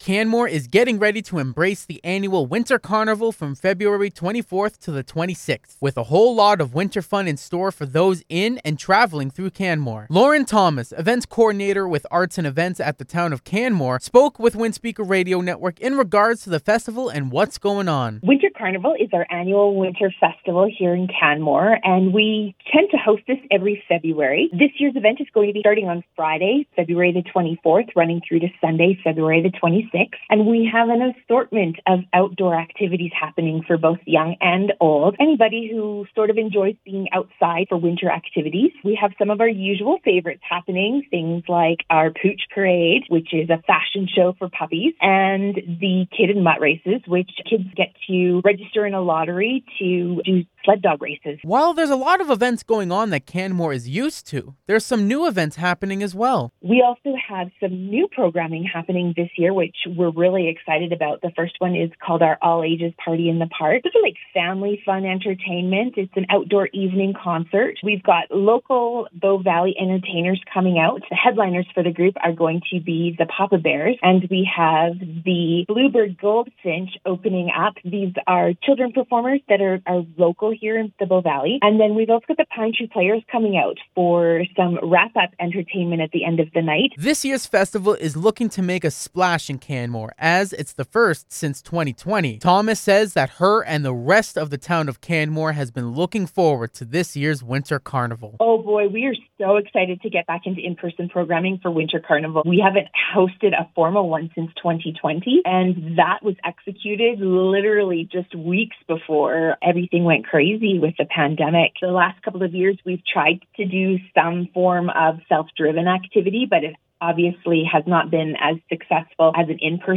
Listen to the full CFWE Interview